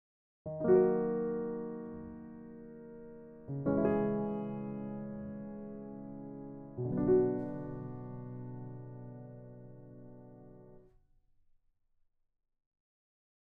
2. Chromatic or Parallel Harmonic Movement
It has an almost Wagnerian character and creates an instant noir feeling.
descending-minor-chords.mp3